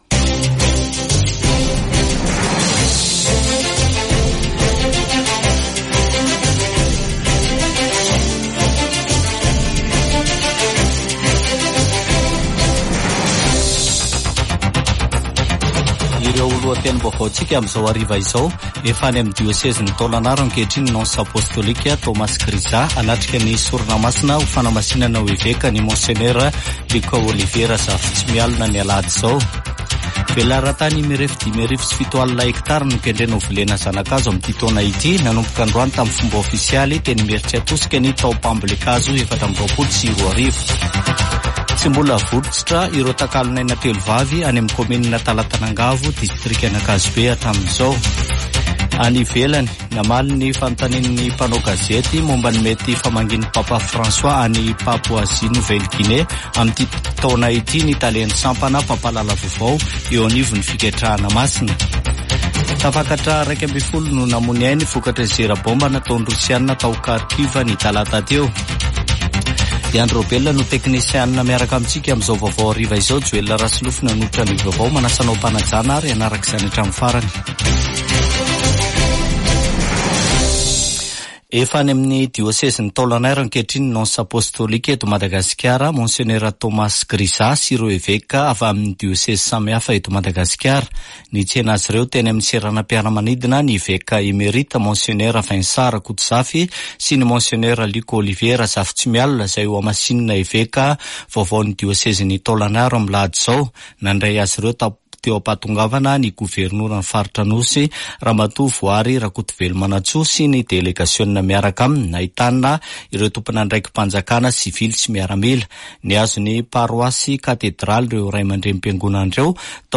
[Vaovao hariva] Zoma 26 janoary 2024